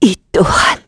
Isolet-Vox_Dead_kr.wav